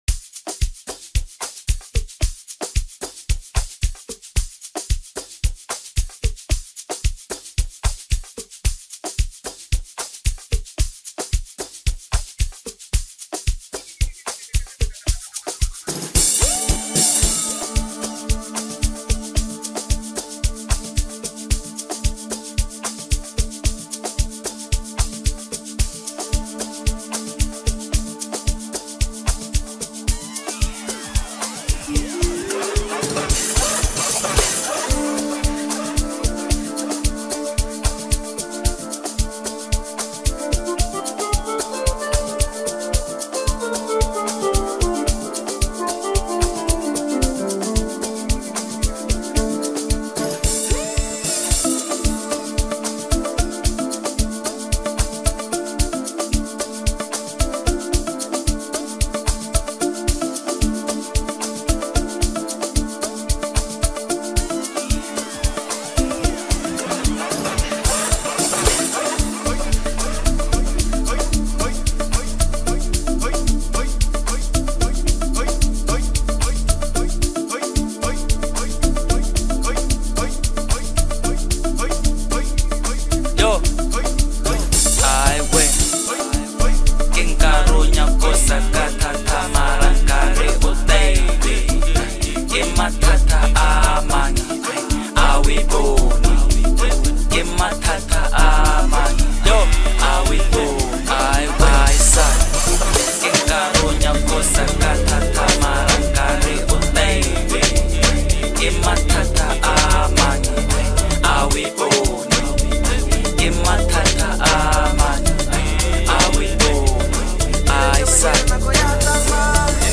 Home » Deep House » Hip Hop » Latest Mix